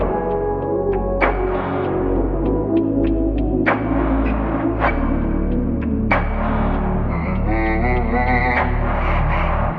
LOOP - NEW FRIENDS.wav